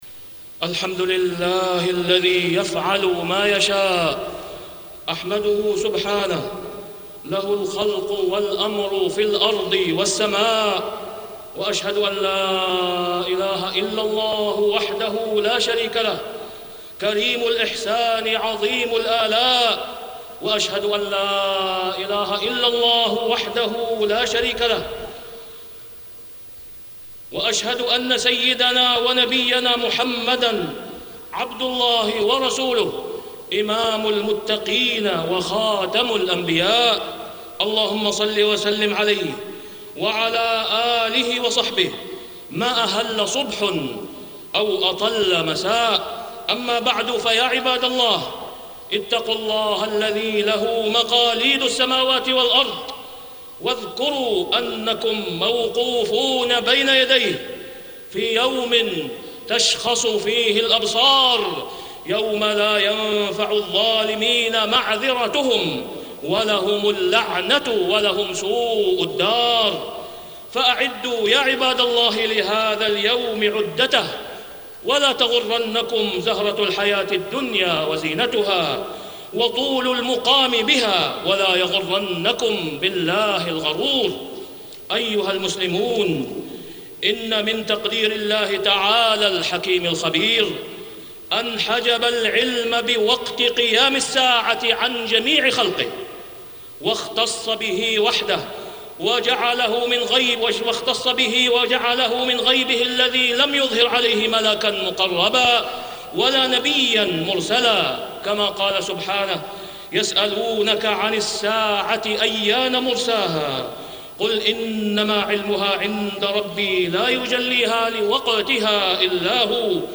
تاريخ النشر ٢٢ صفر ١٤٢٦ هـ المكان: المسجد الحرام الشيخ: فضيلة الشيخ د. أسامة بن عبدالله خياط فضيلة الشيخ د. أسامة بن عبدالله خياط موقف المؤمن من الزلازل